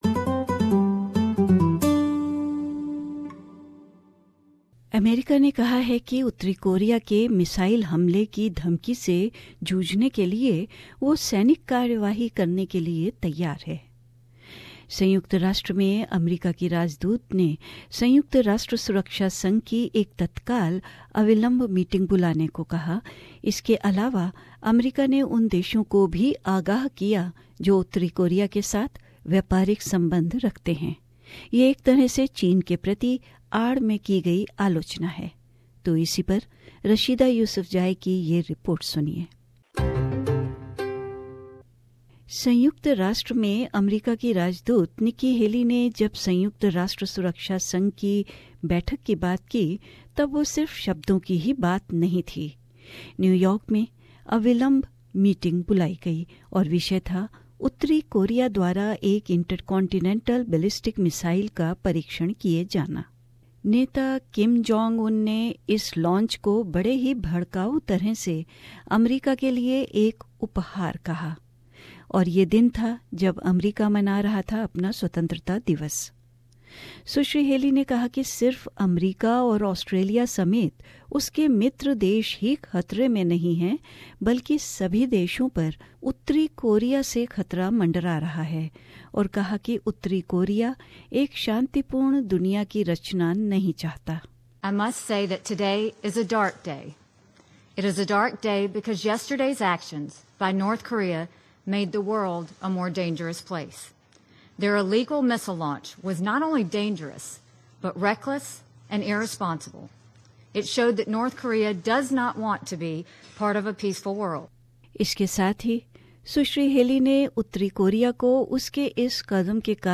The United States ambassador to the United Nations, Nikki Haley, was not mincing words when she stepped up at the UN Security Council.